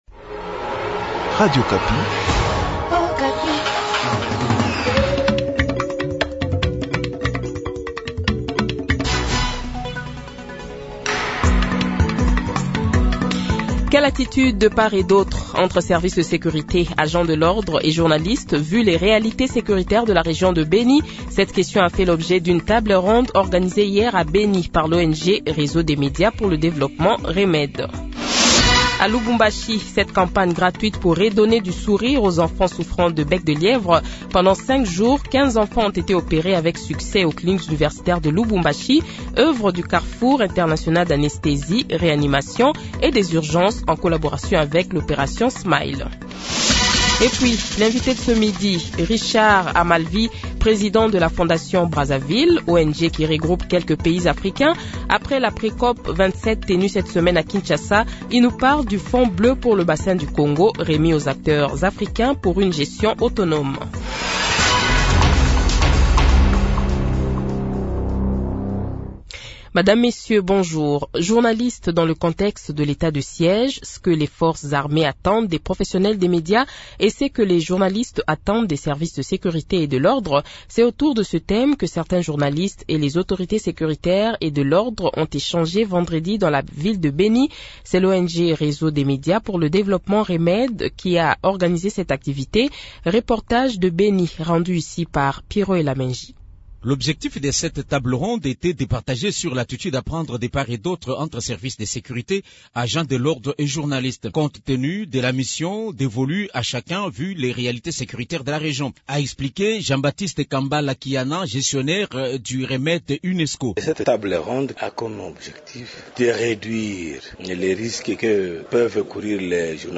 Le Journal de 12h, 08 Octobre 2022 :